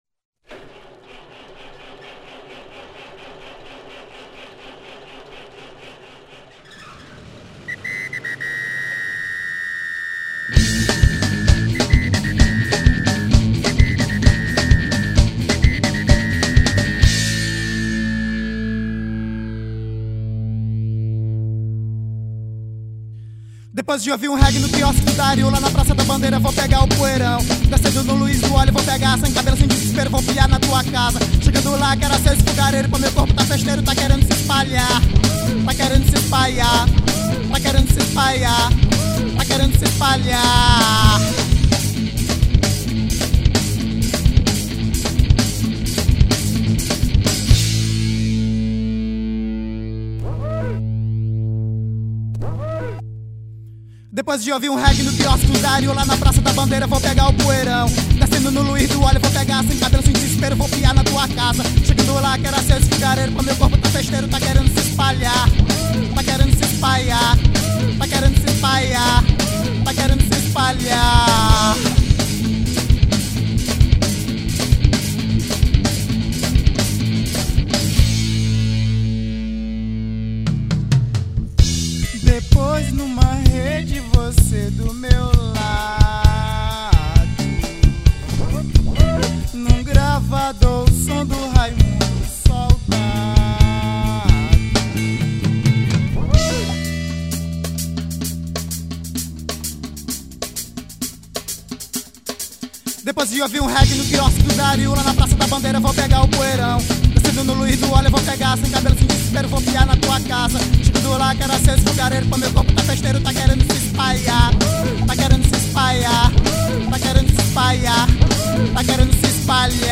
1950   04:23:00   Faixa:     Rock Nacional